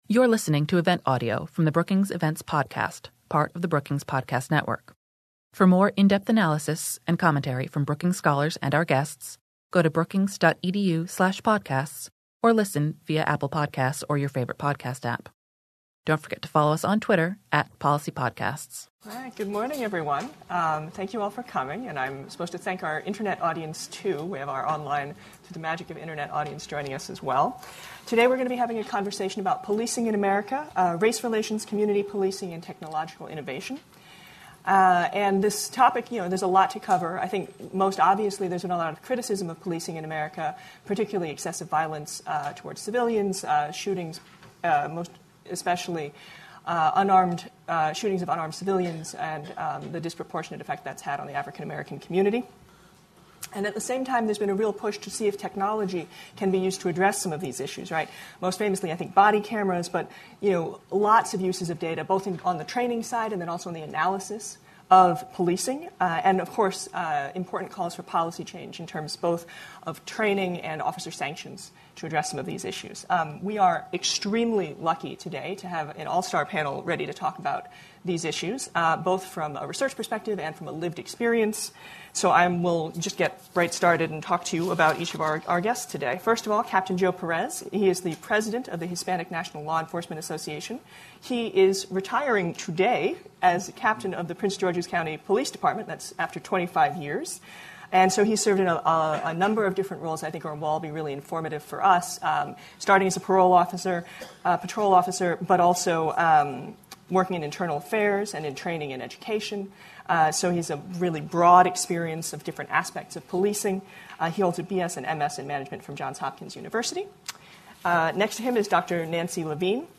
On Oct. 25, Brookings hosted an expert discussion on improving policing in the U.S.
Expert panelists addressed the strengths and weaknesses of data science and technological enhancements for the policing profession, the mental health of police officers, and policies to advance community policing, improve officer well-being, and reduce officer-involved shootings. After the panel, a Q&A session followed.